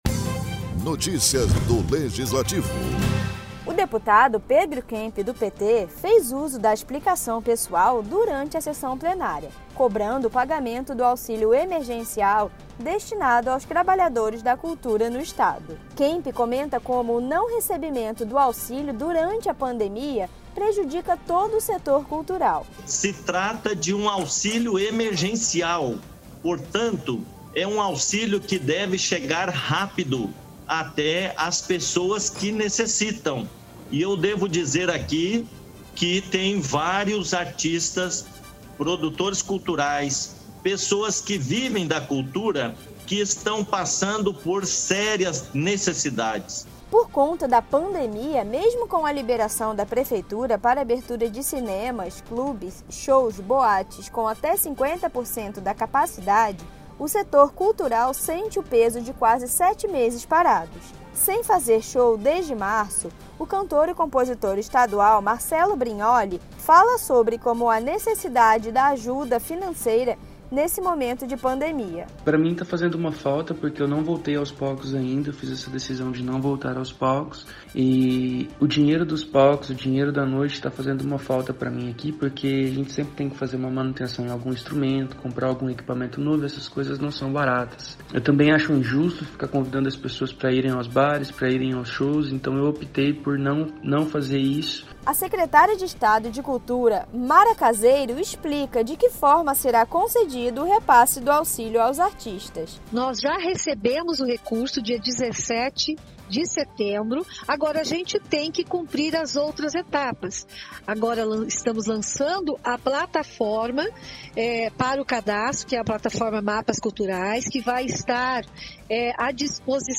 O deputado Pedro Kemp (PT) fez uso da explicação pessoal durante a sessão plenária, cobrando o pagamento do auxílio emergencial destinado aos trabalhadores da cultura no Estado.